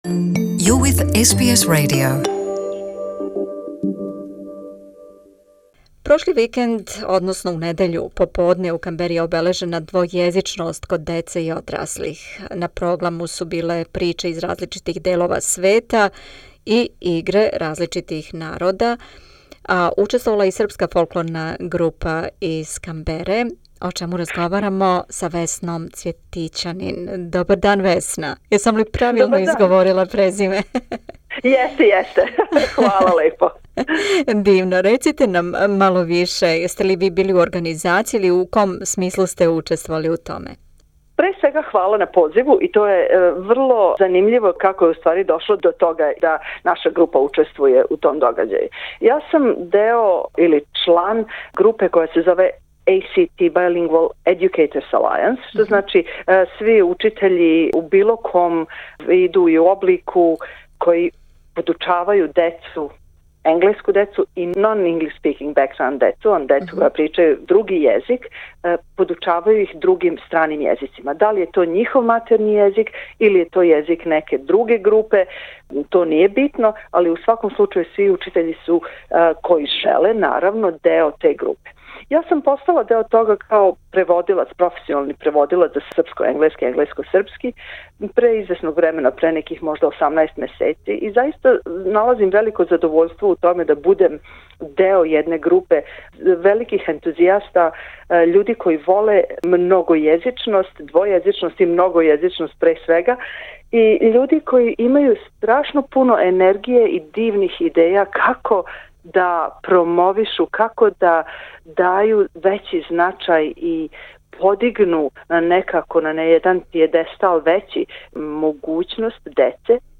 Прошли викенд, у недељу поподне, у Канбери је обележена двојезичност код деце и одраслих. На програму су биле приче из различитих делова света, и биле су казиване на више различитих језика.